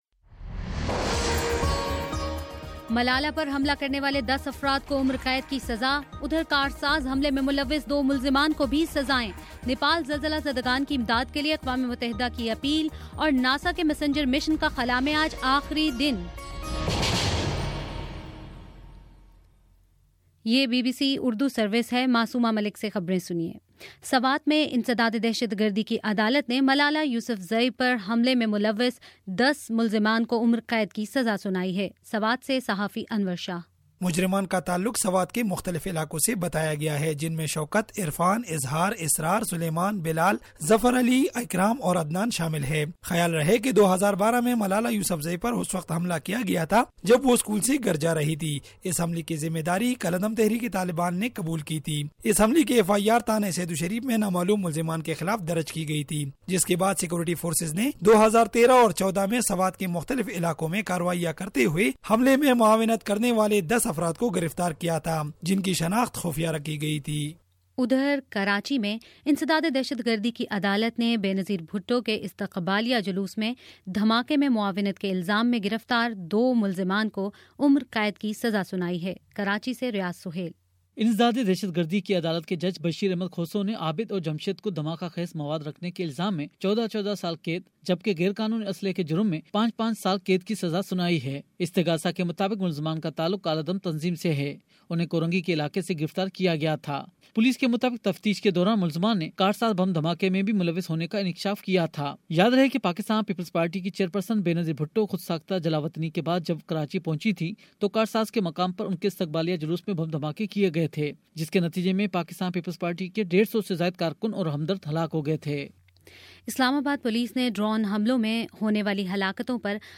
اپریل 30 : شام چھ بجے کا نیوز بُلیٹن